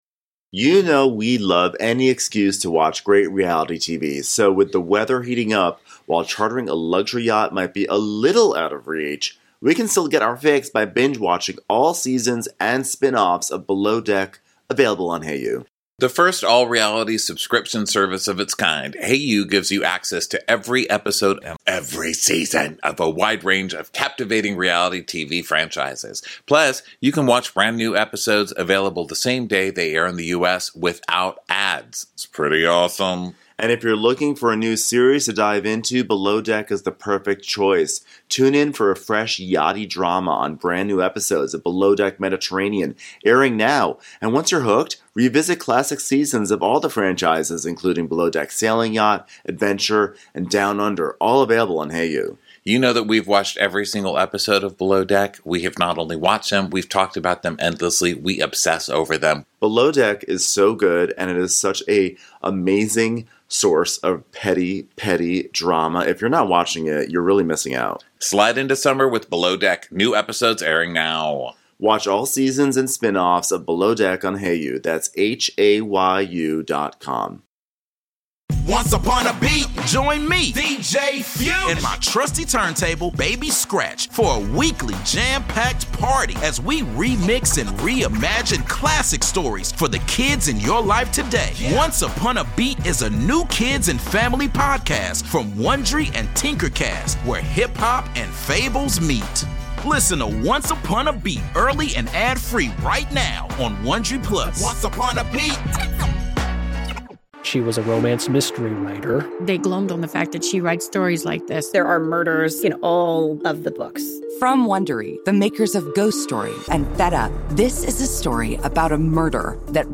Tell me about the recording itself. This is Part 1 of our live show in Birmingham!